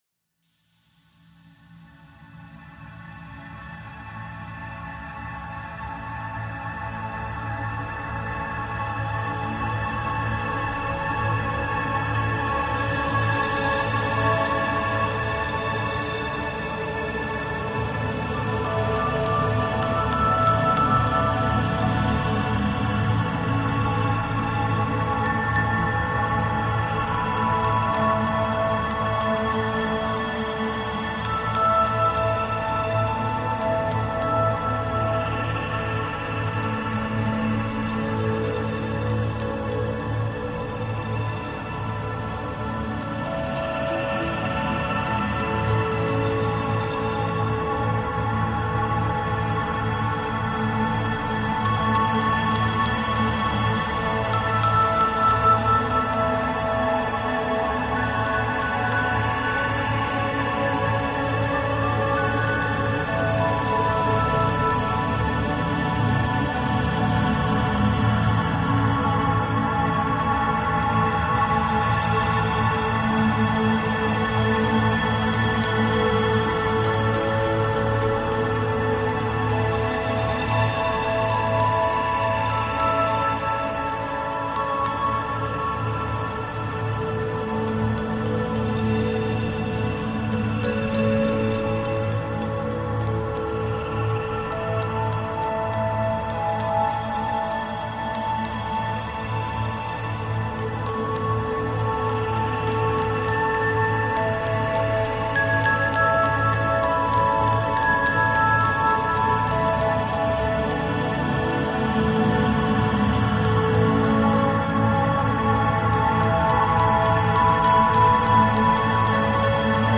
Musique relaxante meditation profonde1
2025 MUSIQUE RELAXANTE, EFFETS SONORES DE LA NATURE audio closed https
Musique-relaxante-meditation-profonde1.mp3